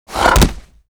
WAV · 83 KB · 單聲道 (1ch)